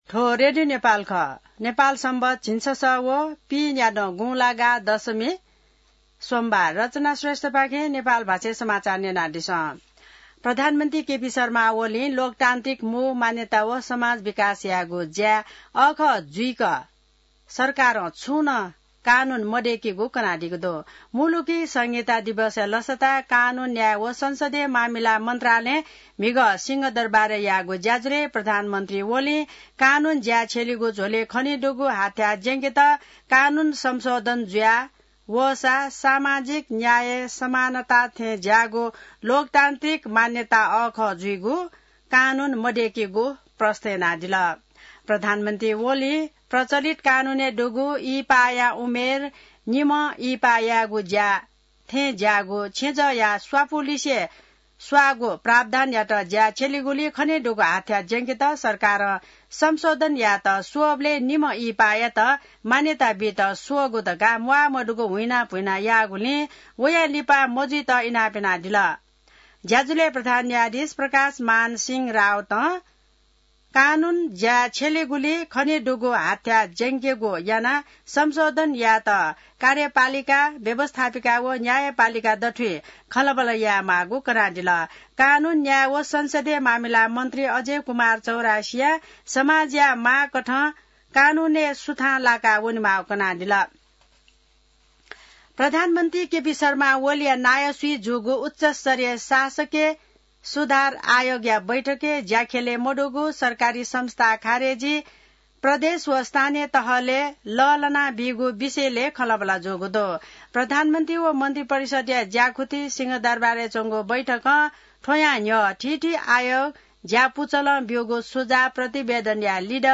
नेपाल भाषामा समाचार : २ भदौ , २०८२